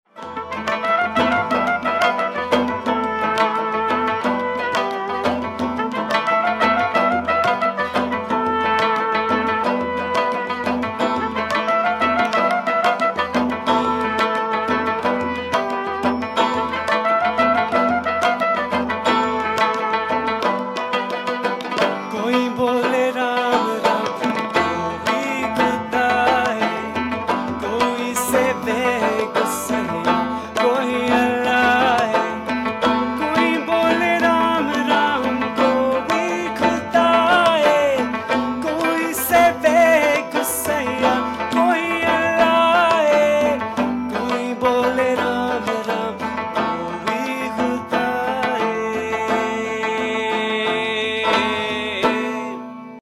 Never heard before style of kirtan with rabab & trumpet. Our rendition of Koi Bolai Ram at the San Jose Gurdwara.